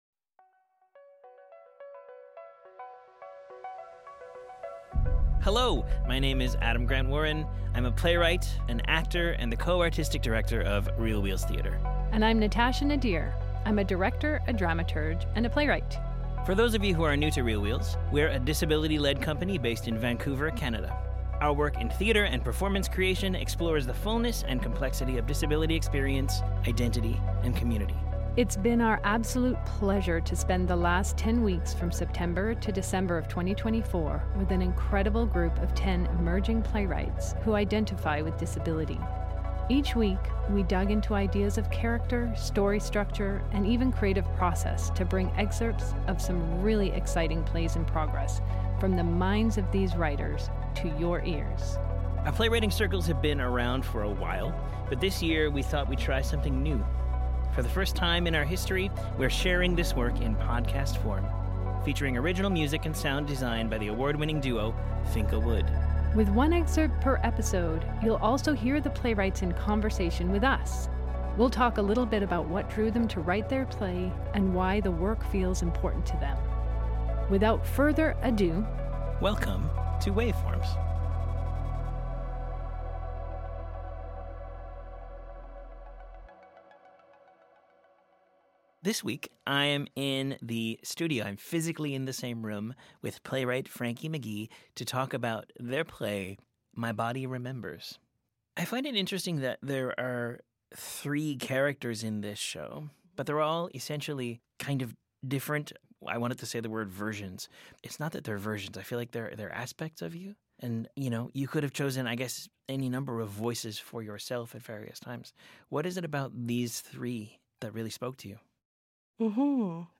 This production was recorded and broadcast from the unceded and ancestral lands of the Musqueam, Squamish, and Tsleil-Waututh Nations, at GGRP Sound Studios.